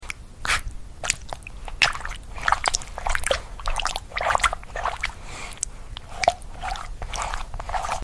Just the sound of a good boy eating space.